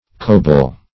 Coble \Co"ble\, n. [AS. cuopel; cf. W. ceubal skiff, ferryboat.]